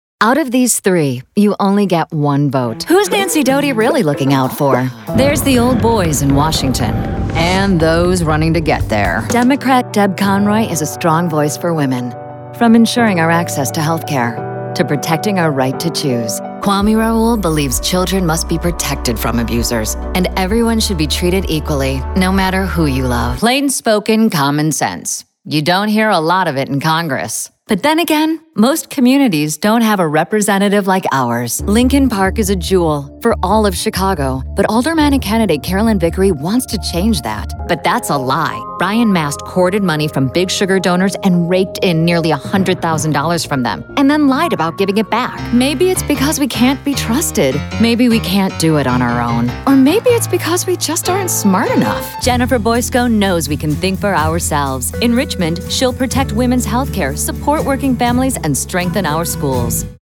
chicago : voiceover : commercial : women